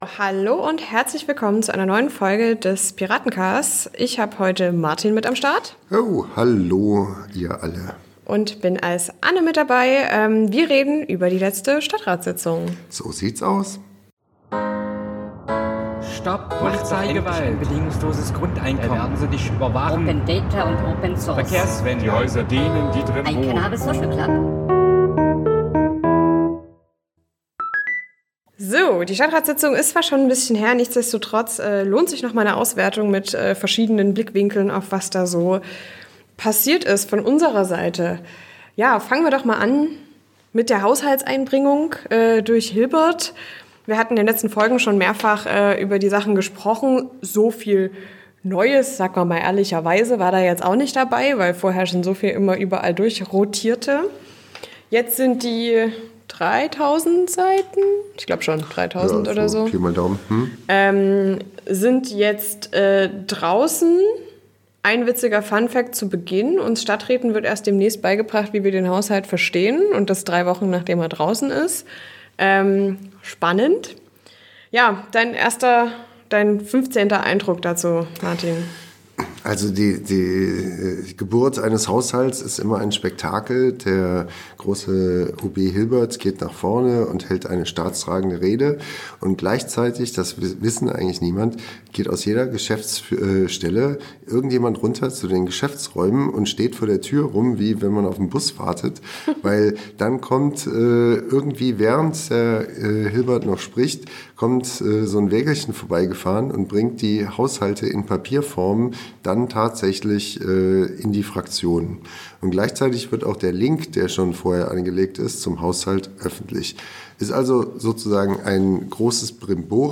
Beschreibung vor 1 Jahr Unsere Stadträt:innen Anne und Martin werten die letzte StaDDratssitzung aus. Dabei geht es neben einem kurzen Schwenk zum Haushalt hin zu weiteren Klima-Abfucks und dem scheinbar wichtigstem Thema überhaupt: Der Wasserskianlage. Am Ende gibt es den Ausblick auf einen gemeinsamen Antrag mit der SPD, der soziale Projekte retten kann.